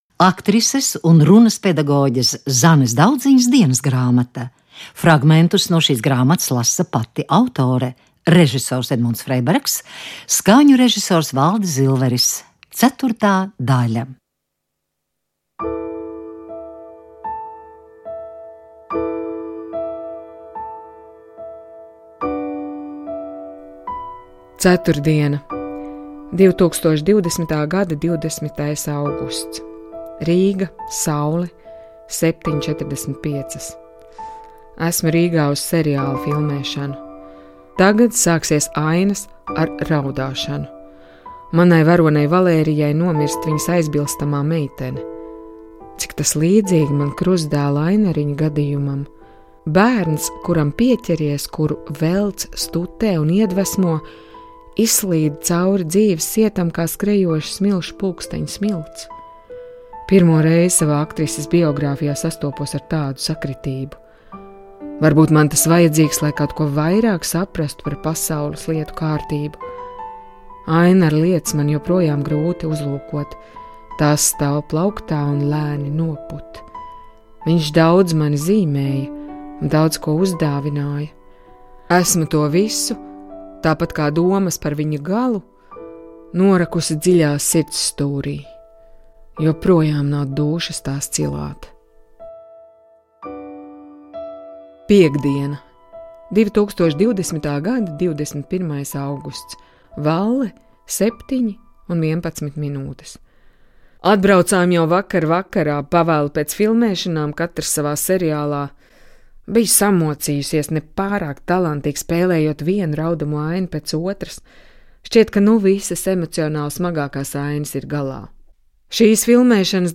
Radioteātris turpina raidīt aktrises un runas pedagoģes Zanes Daudziņas "Dienasgrāmatas" ierakstus. 4. daļa. Fragmentus lasa pati autore.